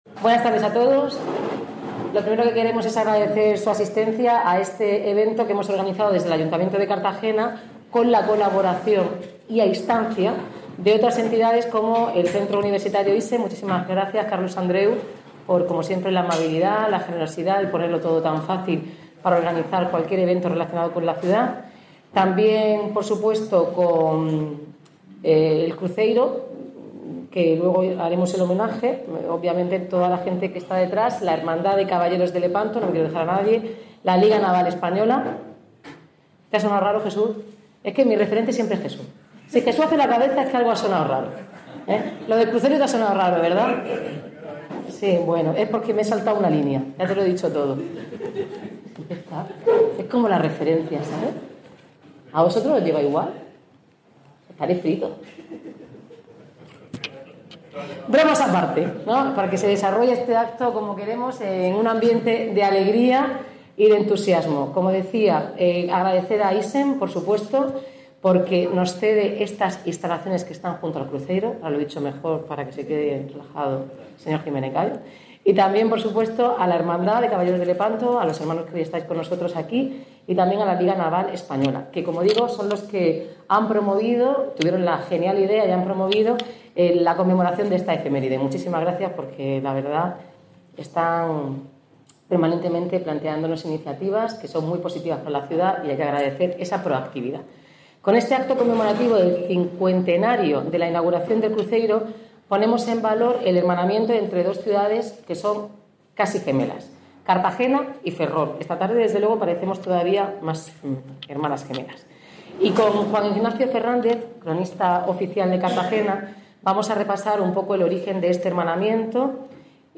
Declaraciones de la alcaldesa, Noelia Arroyo